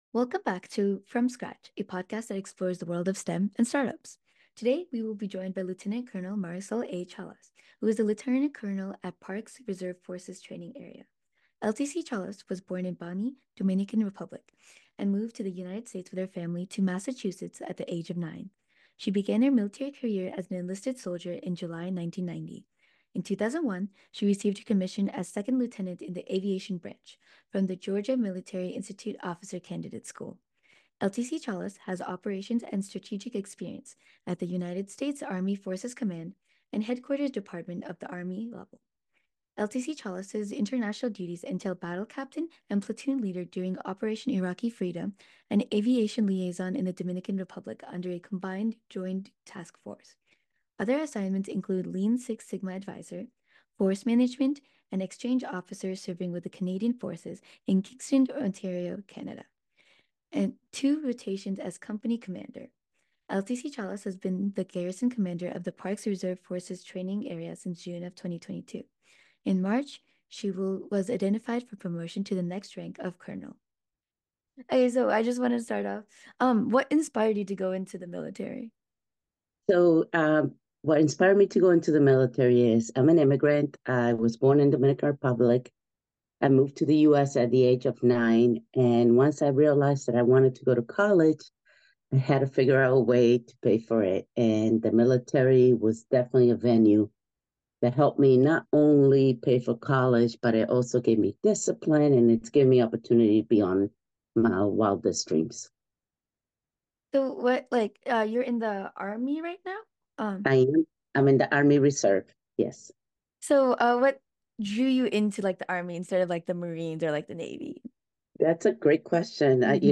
Welcome to the second episode, and first live recording of From Scratch.